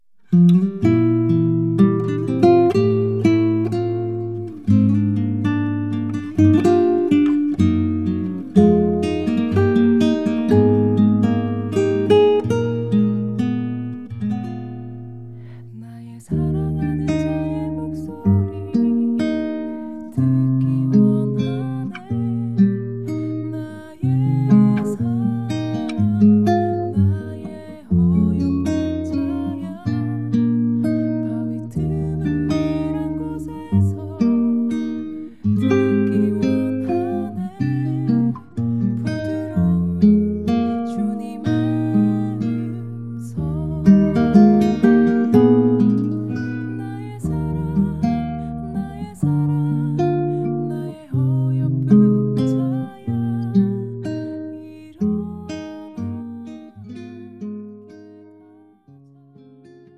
음정 -1키 4:02
장르 가요 구분 Voice MR
가사 목소리 10프로 포함된 음원입니다